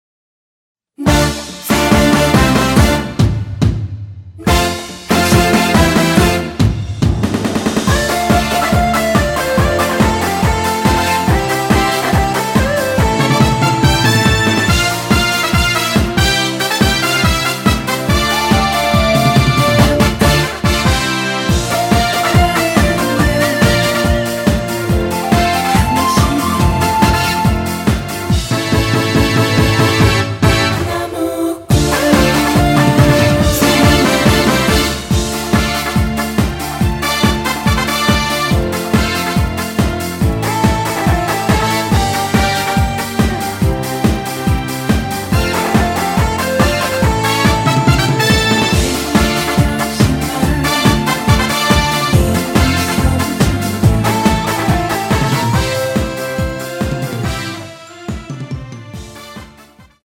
(-1) 내린 코러스 포함된 MR 입니다.
Dm
◈ 곡명 옆 (-1)은 반음 내림, (+1)은 반음 올림 입니다.
앞부분30초, 뒷부분30초씩 편집해서 올려 드리고 있습니다.